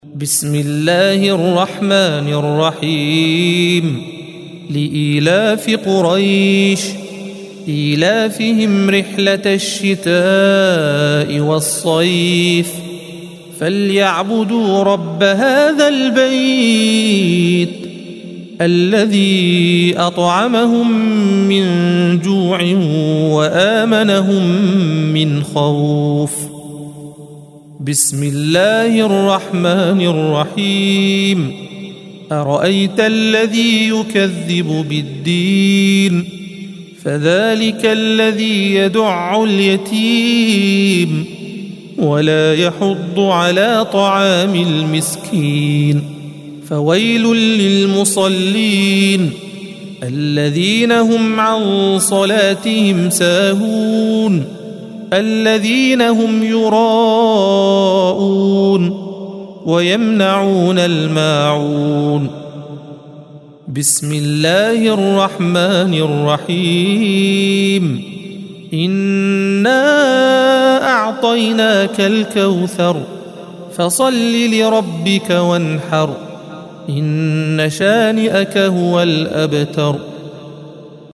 الصفحة 602 - القارئ